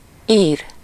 Ääntäminen
IPA : /ɹaɪt/ US : IPA : [ɹaɪt] UK